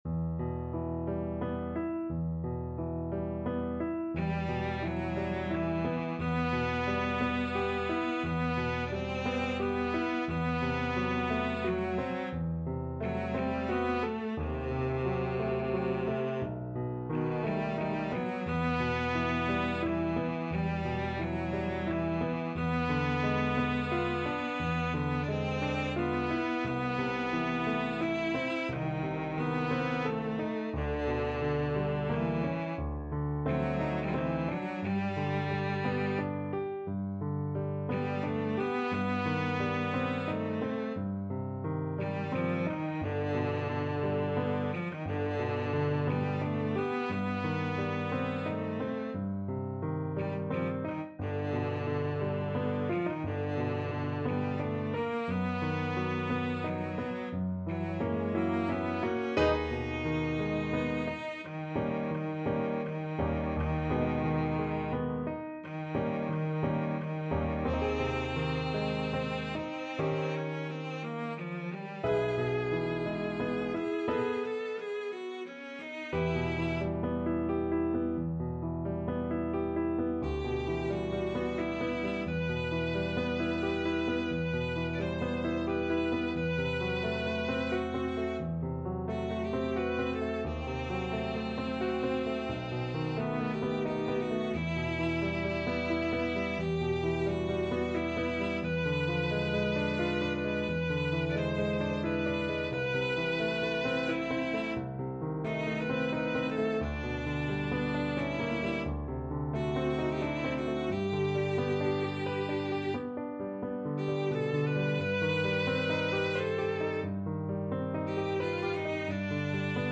Cello version
3/4 (View more 3/4 Music)
~ = 88 Malinconico espressivo
Classical (View more Classical Cello Music)